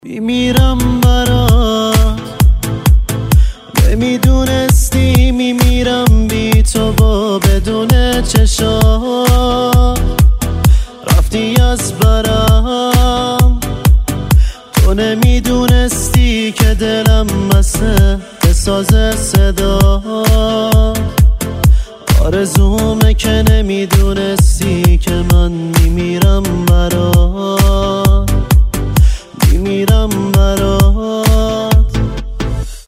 زنگ خواننده ایرانی